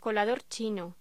Locución: Colador chino
voz